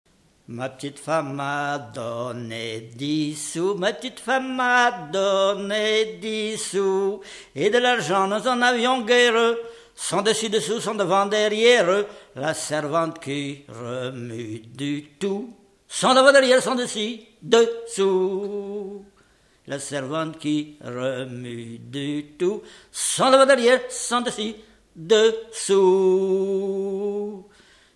Genre énumérative
Catégorie Pièce musicale inédite